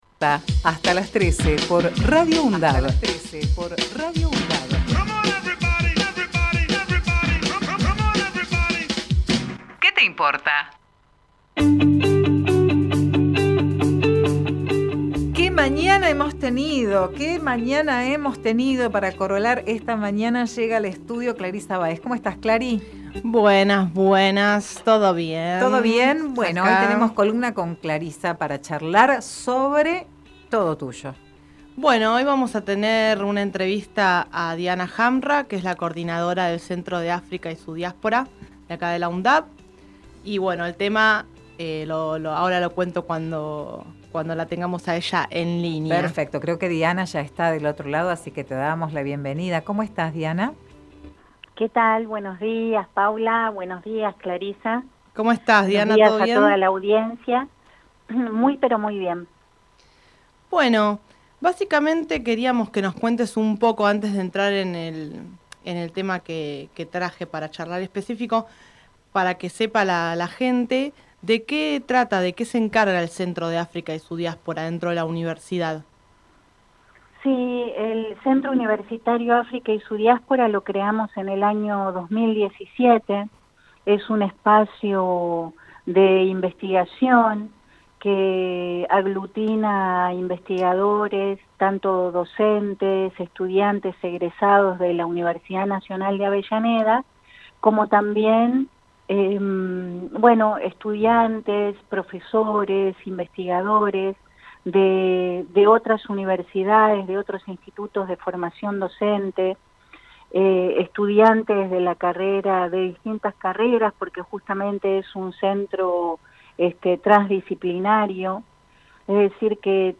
Compartimos la entrevista realizada en "Que te importa?!"